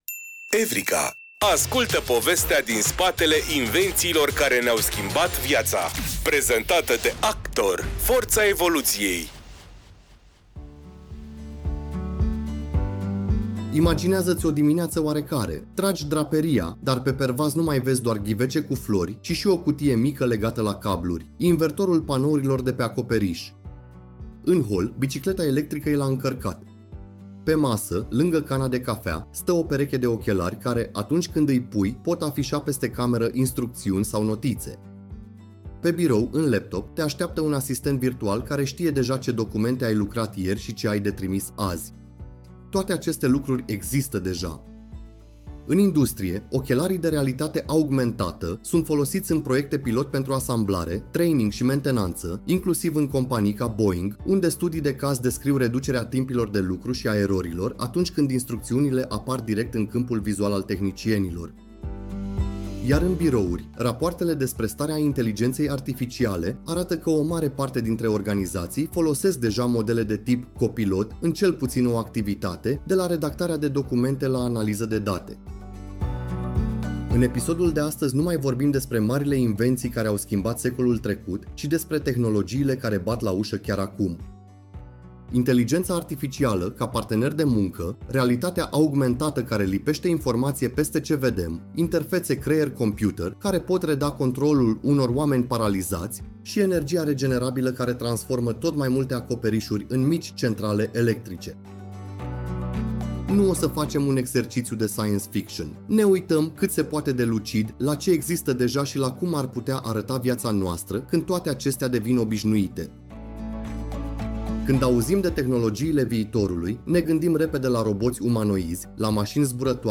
Cu un ton cald și curios, „Evrika” transformă știința și tehnologia în aventuri umane, pe înțelesul tuturor. Producția este realizată cu ajutorul inteligenței artificiale, combinând cercetarea documentară cu narațiunea generată și editată creativ de echipa SOUNDIS România.